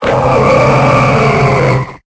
Cri de Wailord dans Pokémon Épée et Bouclier.